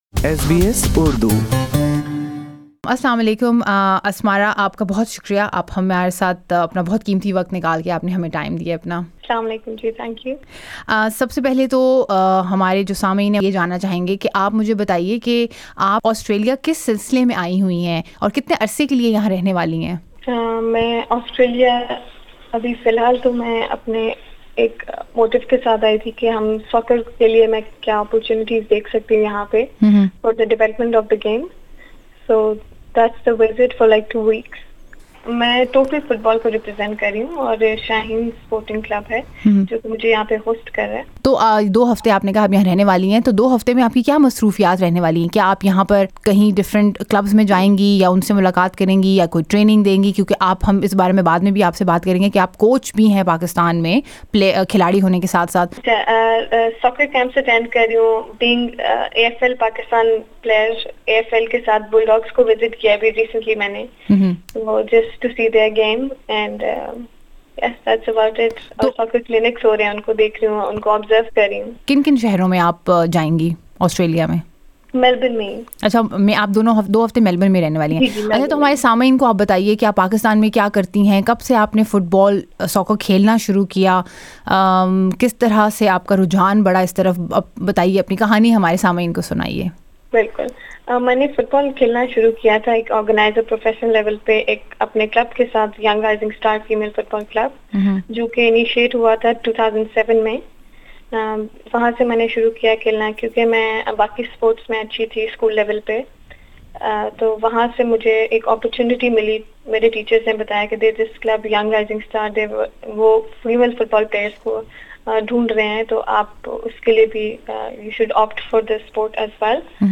She speaks softly and slowly, and not a lot.